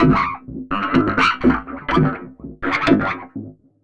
tx_synth_125_almost_CGAb.wav